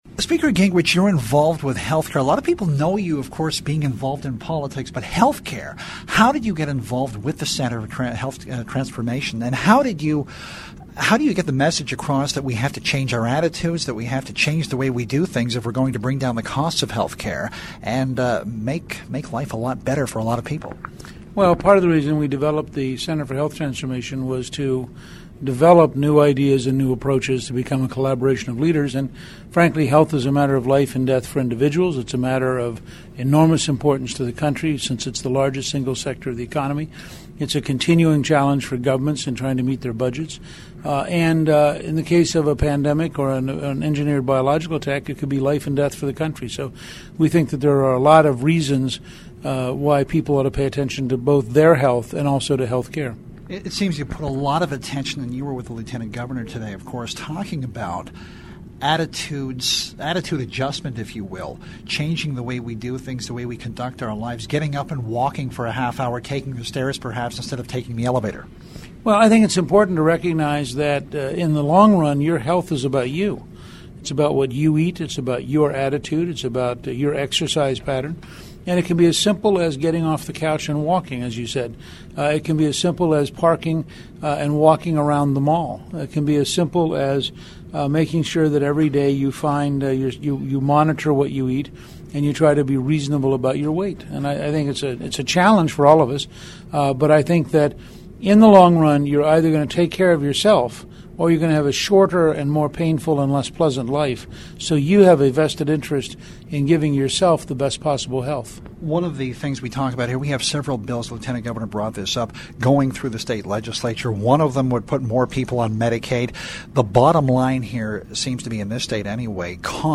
In an interview with the Missourinet, Gingrich talked about individuals changing attitudes about their own health and well being.